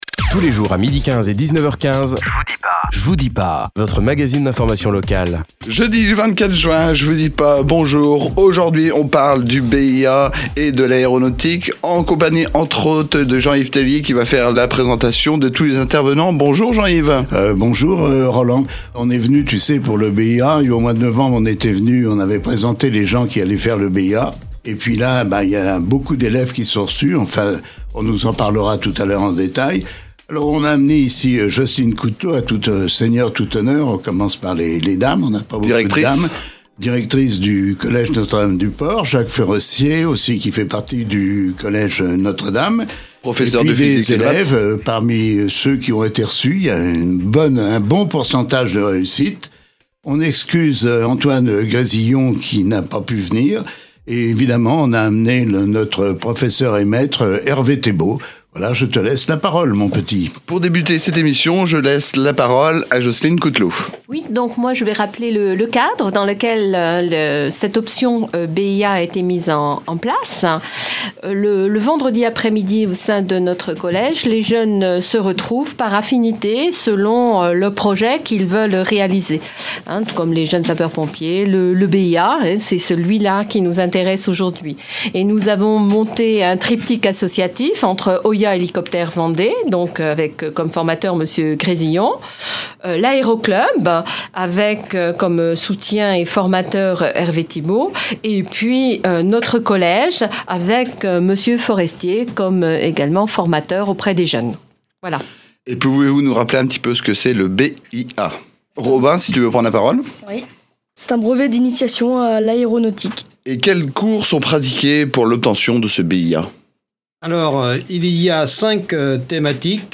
Les podcasts Interview - Page 162 sur 174 - La FRAP